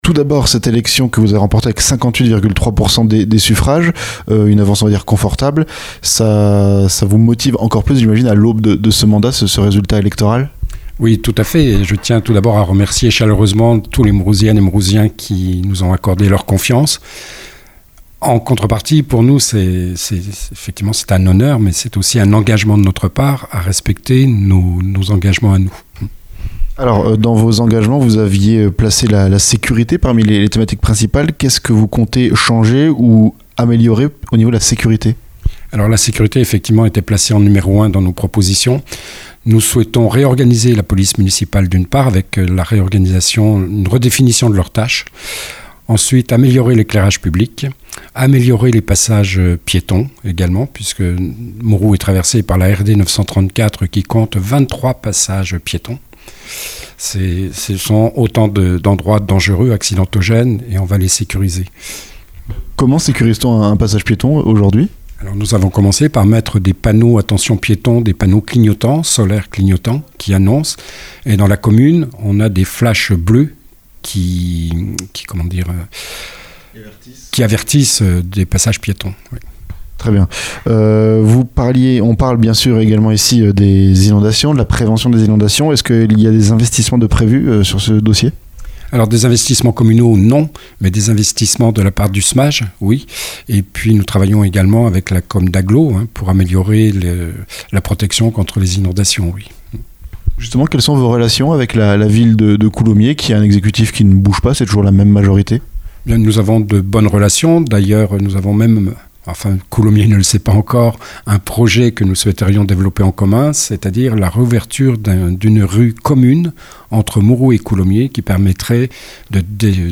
MOUROUX - Entretien avec Jean-Louis Bogard, réélu maire